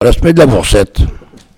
Sables-d'Olonne (Les)
locutions vernaculaires